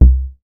MoogTom 002.WAV